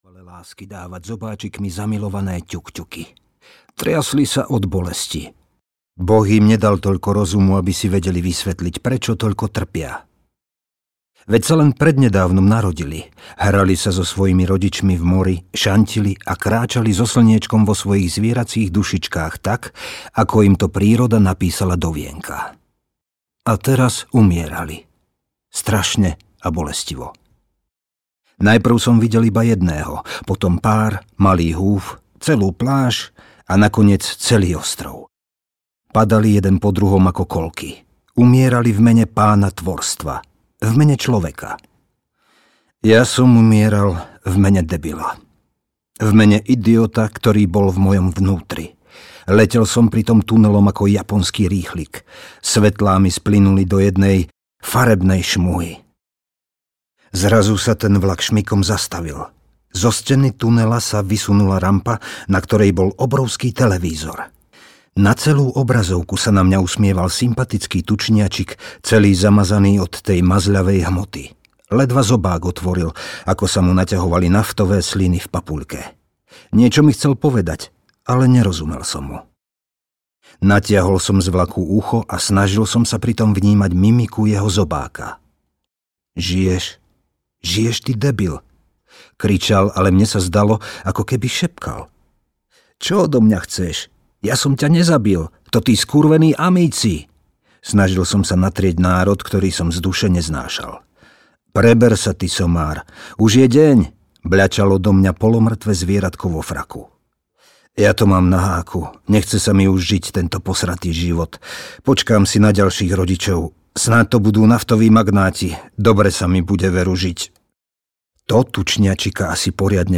Raz aj v pekle vyjde slnko audiokniha
Ukázka z knihy